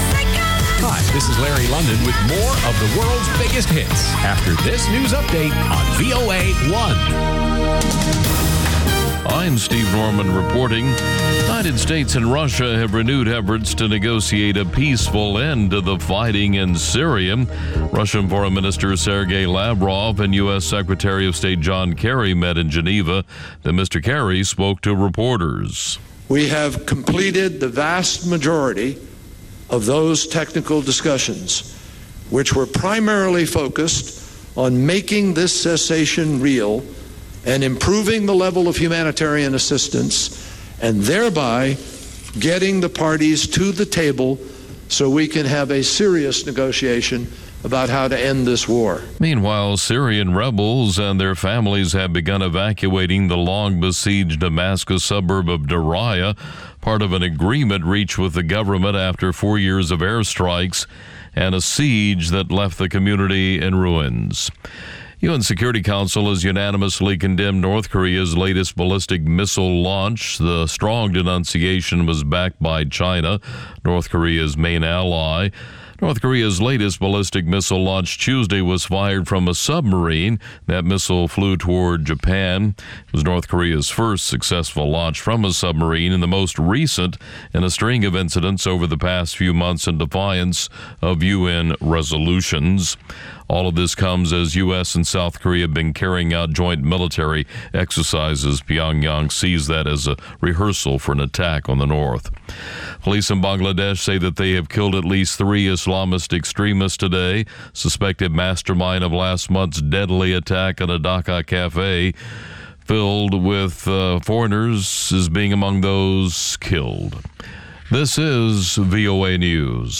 ყოველ შაბათს რადიო თავისუფლების პირდაპირ ეთერში შეგიძლიათ მოისმინოთ სპორტული გადაცემა „მარათონი“.